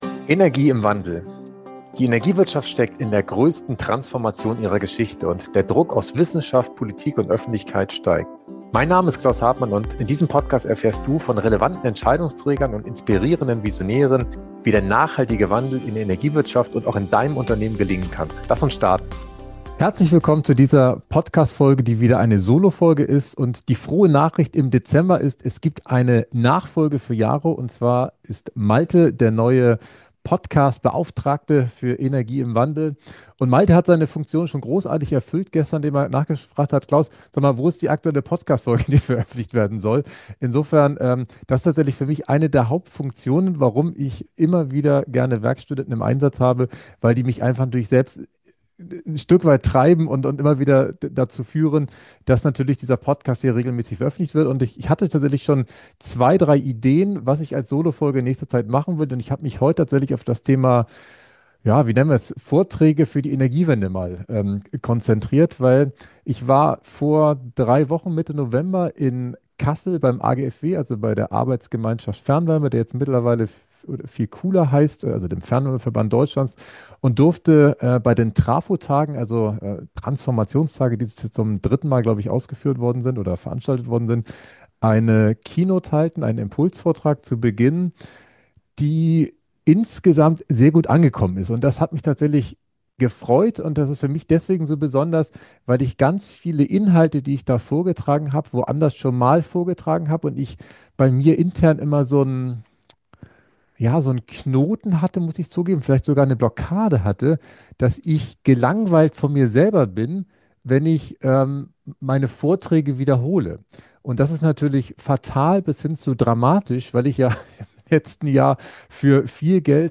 In dieser persönlichen Solofolge nehme ich dich mit in meine eigene Transformation – direkt aus dem Jahresendgefühl 2025.